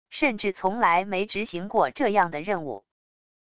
甚至从来没执行过这样的任务wav音频生成系统WAV Audio Player